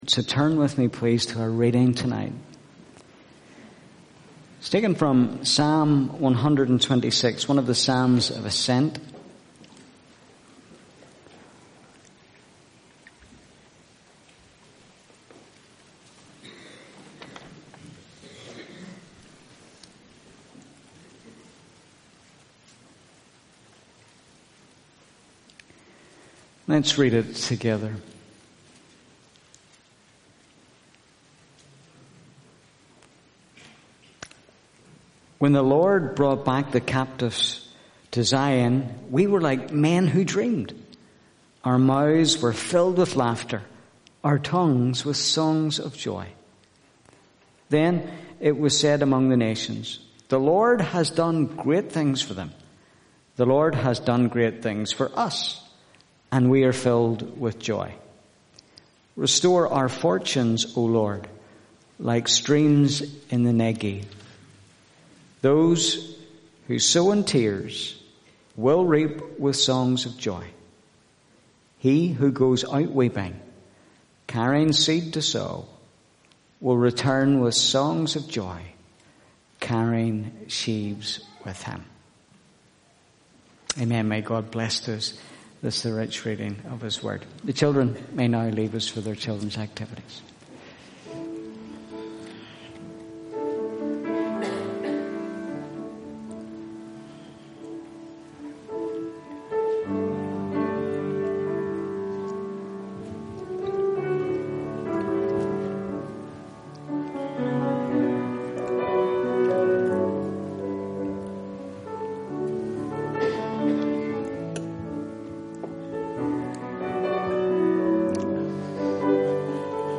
Harvest Service